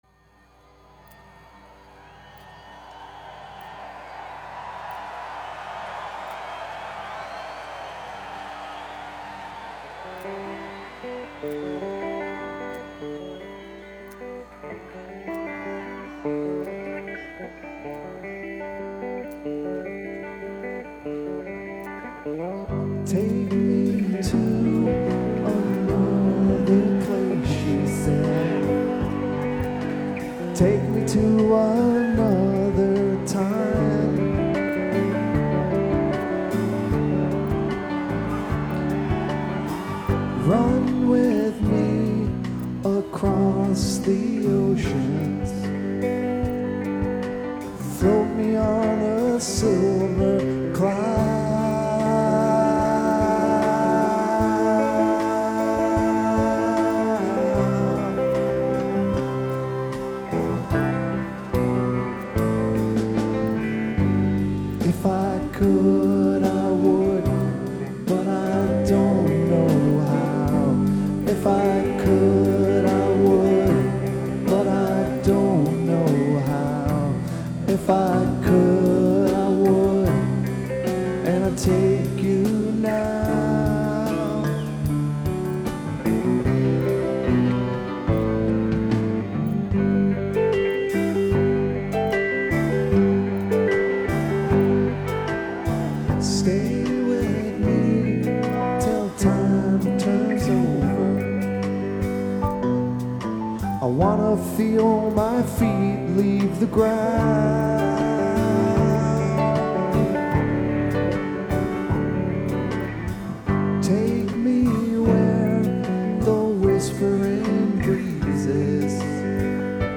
guitar solo
soundboard recording